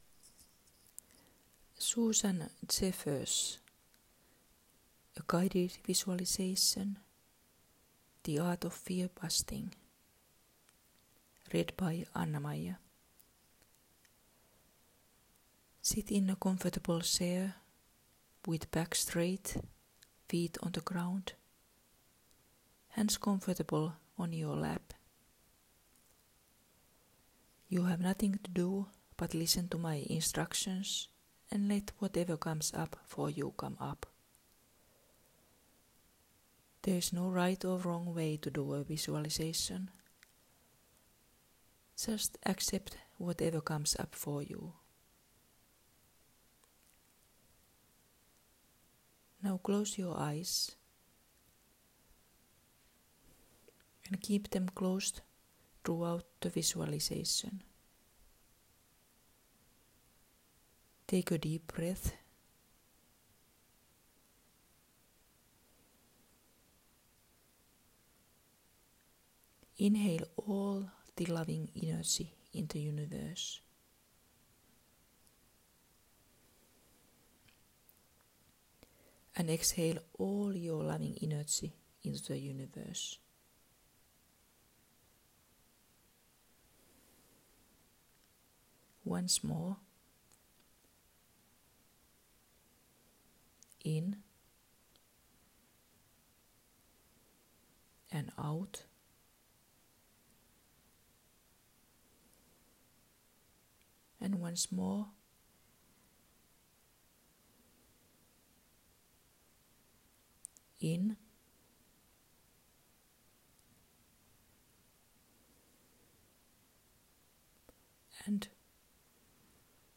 Susan Jeffers: A guided vizualisation, The Art of Fearbusting.